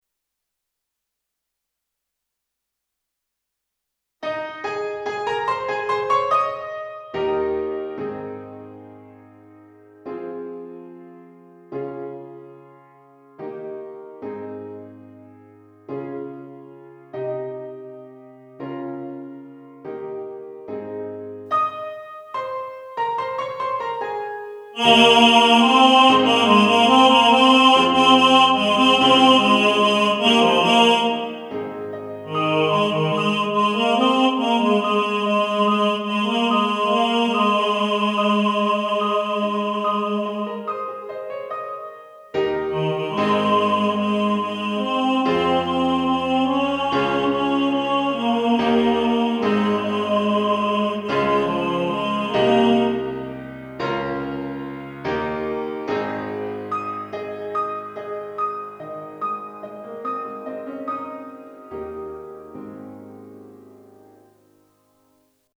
Simple-Gifts-Tenor.mp3